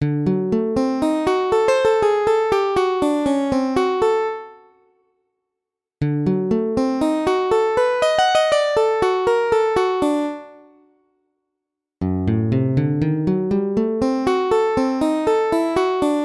D7のフレーズ
D7 アルペジオ　フレーズ
D7-licks-arpeggio-caravan.mp3